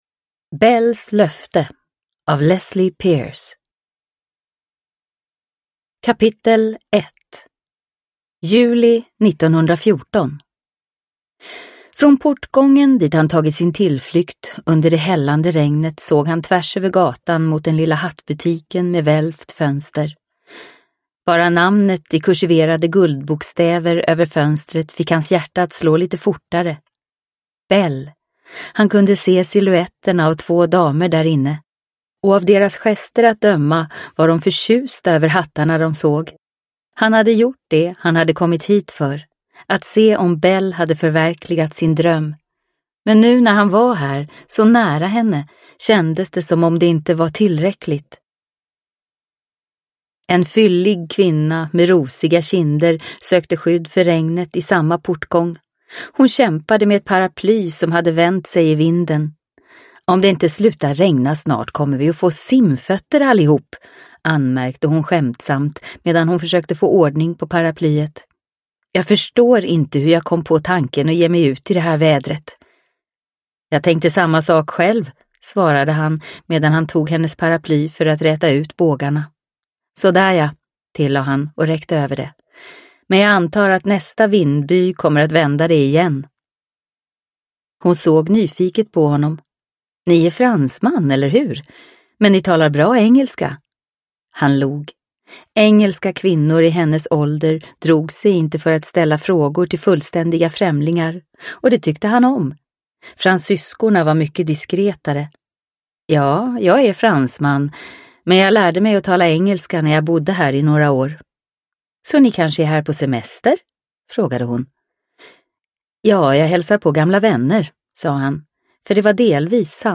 Belles löfte – Ljudbok – Laddas ner